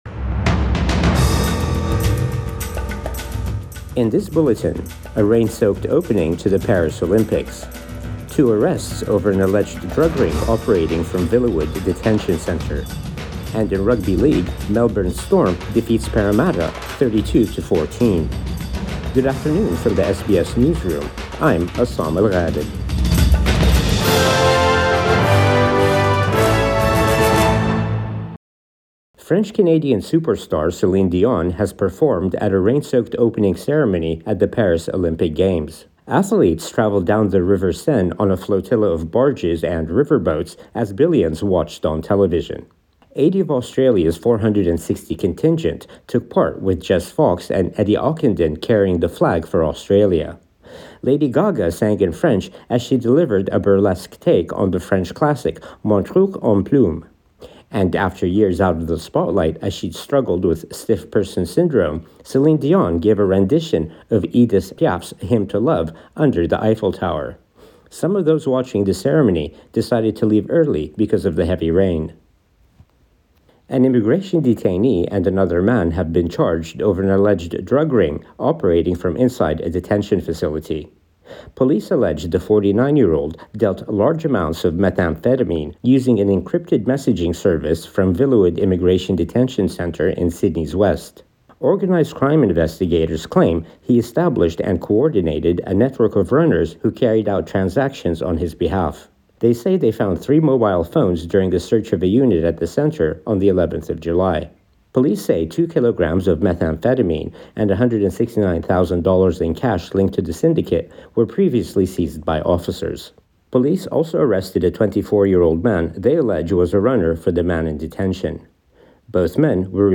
Midday News Bulletin 27 July 2024